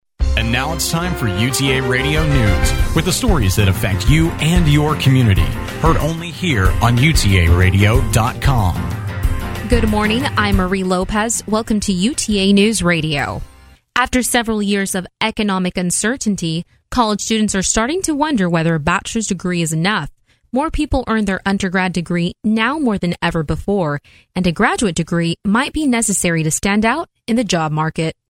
Best Newscast  Radio
RadioNewscast13.mp3